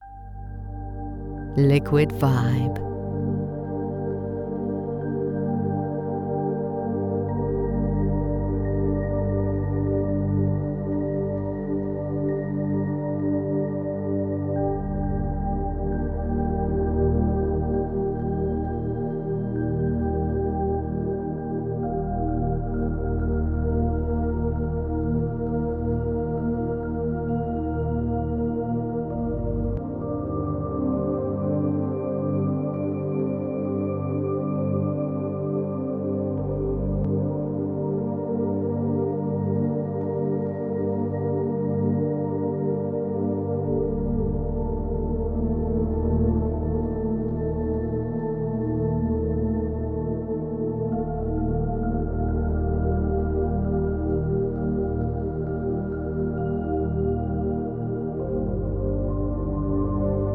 Enjoy a 1-Minute Sample – Purchase to Hear the Whole Track